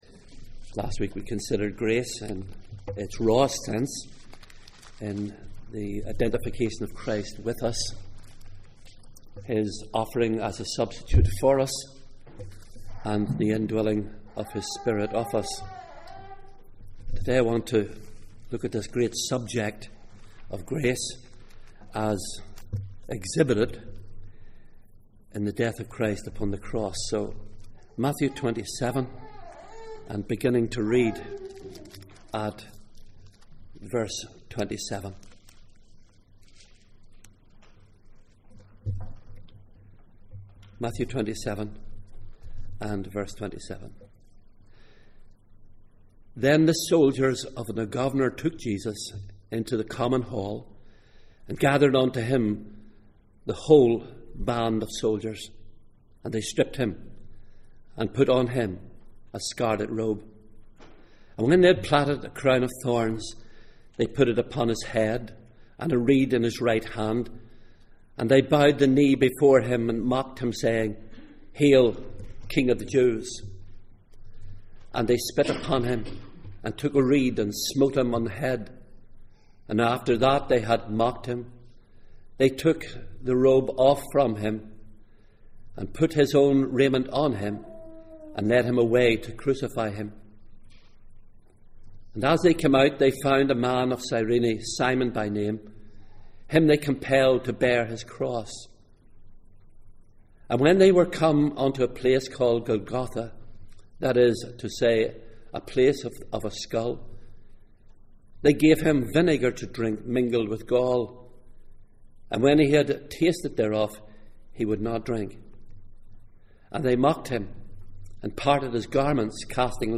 Grace Passage: Matthew 27:27-49, Psalm 22:1-16 Service Type: Sunday Morning %todo_render% « What we are in Jesus Christ How can a person be right with God?